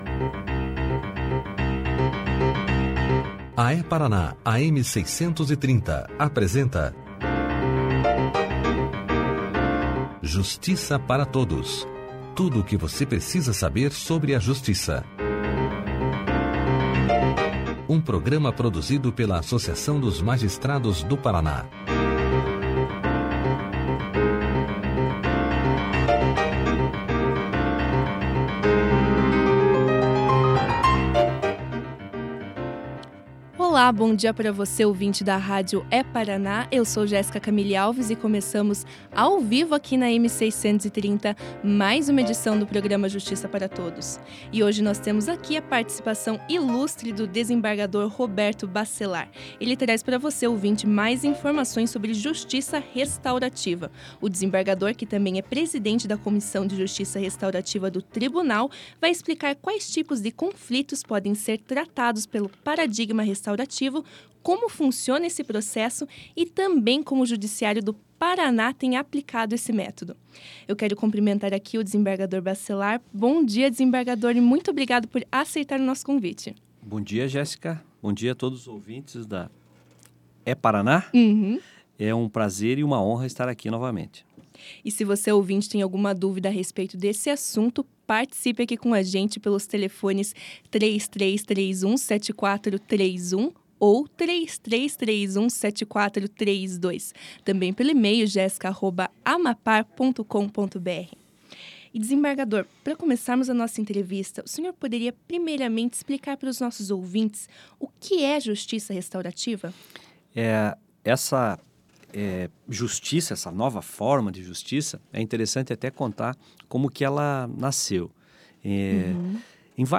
Clique aqui e ouça a entrevista do desembargador Roberto Bacellar sobre Justiça Restaurativa e Mediação na íntegra.